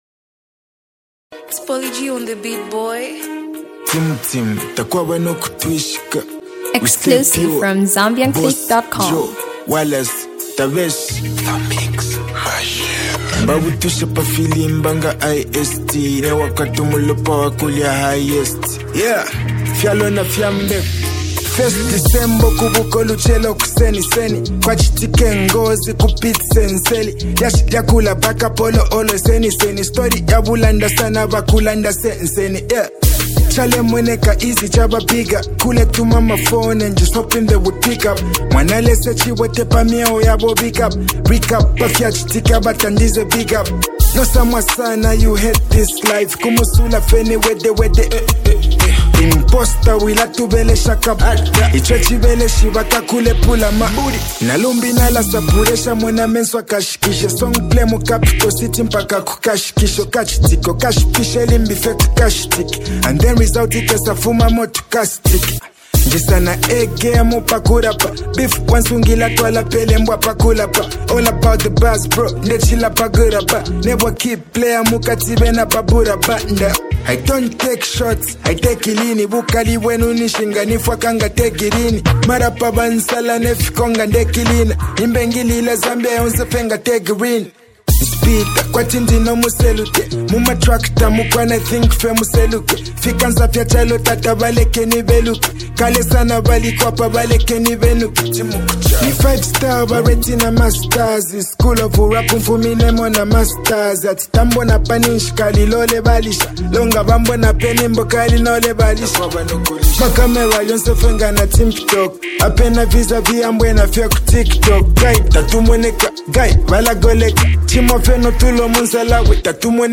freestyle single
continues dropping bars after bars